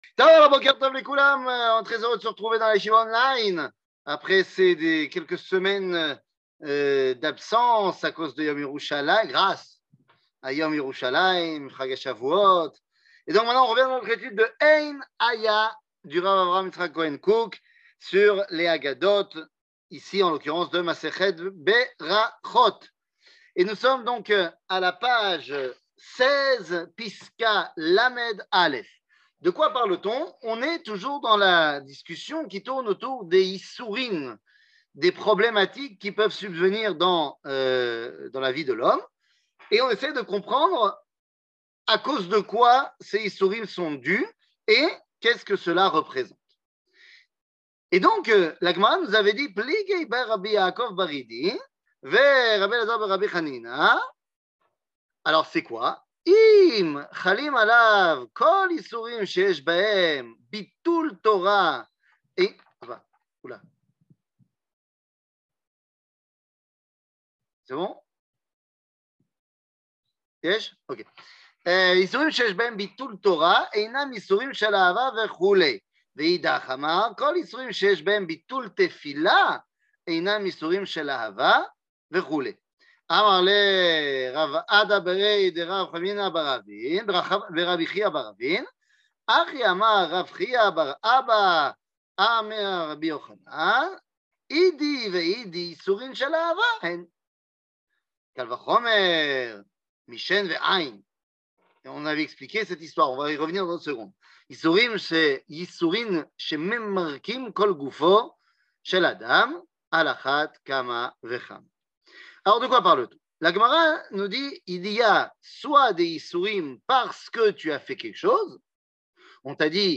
קטגוריה Ein Haya Partie 22 00:44:09 Ein Haya Partie 22 שיעור מ 12 יוני 2022 44MIN הורדה בקובץ אודיו MP3